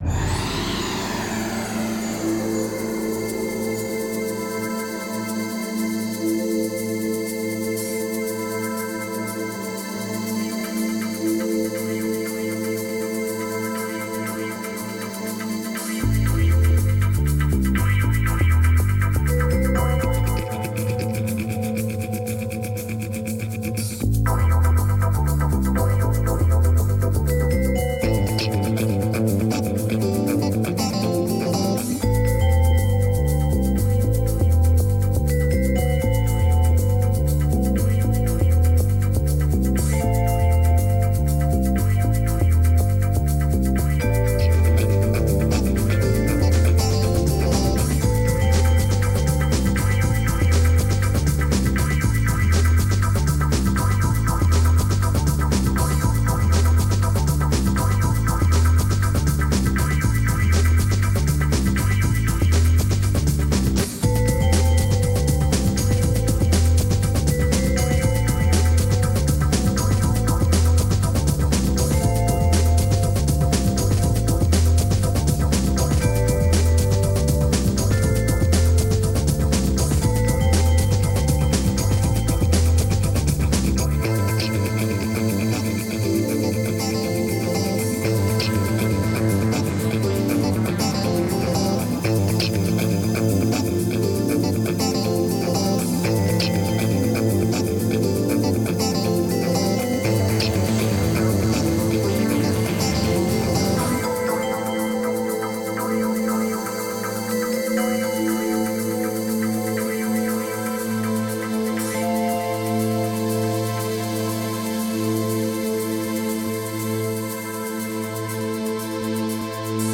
PromoFilm
6 min., Stereo